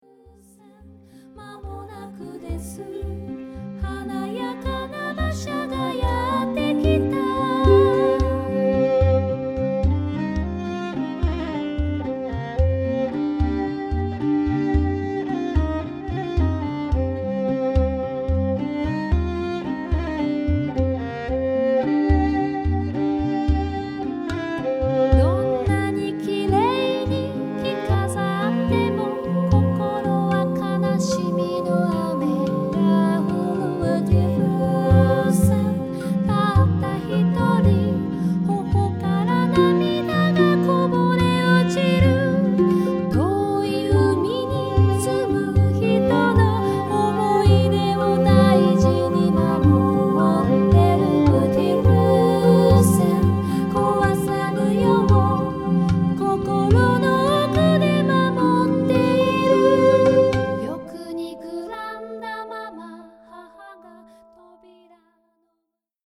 Swedish Folk Song